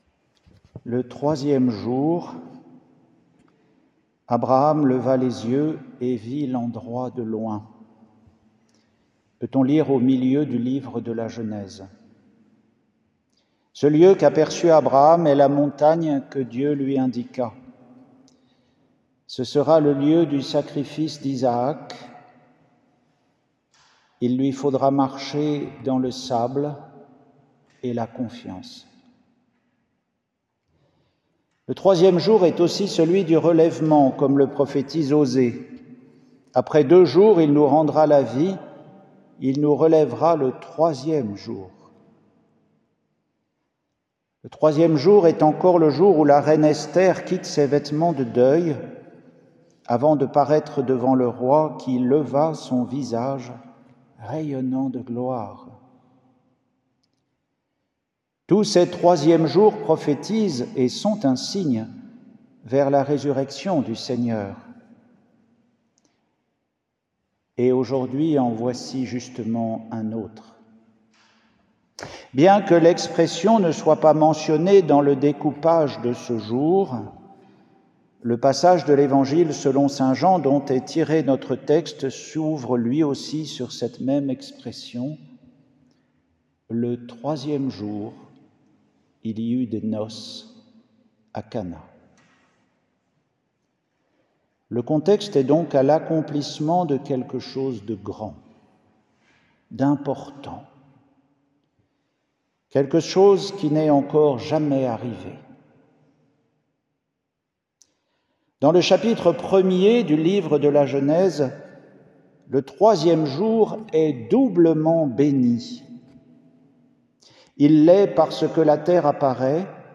Retrouvez les méditations d’un moine sur les lectures de la messe du jour.
Homélie pour le 2e dimanche du Temps ordinaire